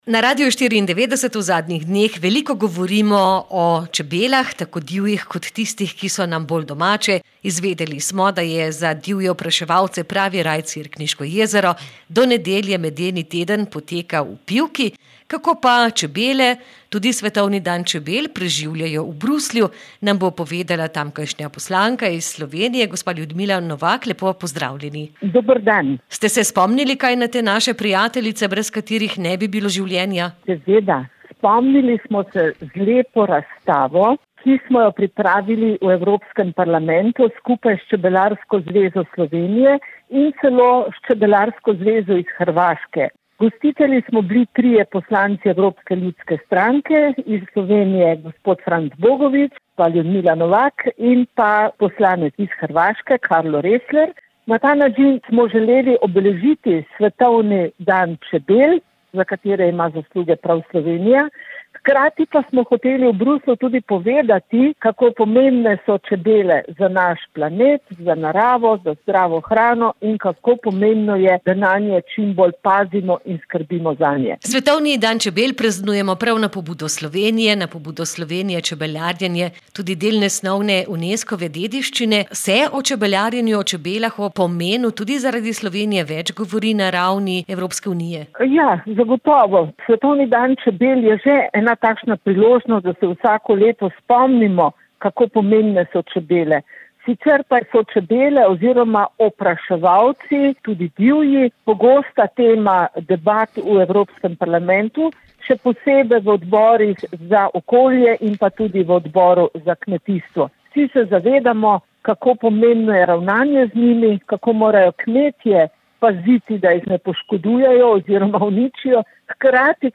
ljudmila-novak-pogovor.mp3